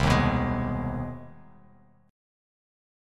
CmM7#5 chord